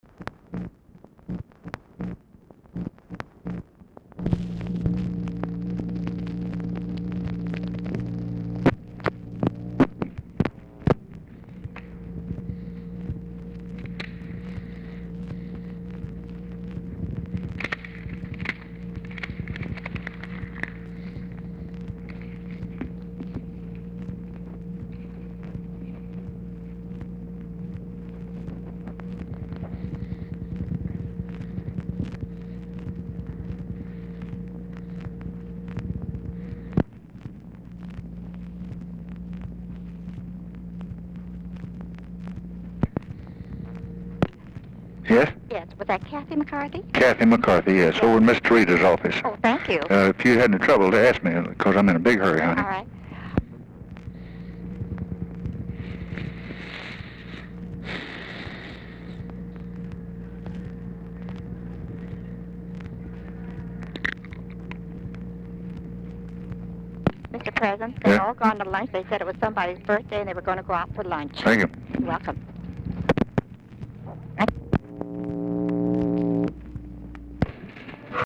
Telephone conversation
LBJ IS ON HOLD FOR MOST OF CALL
Format Dictation belt
Speaker 2 TELEPHONE OPERATOR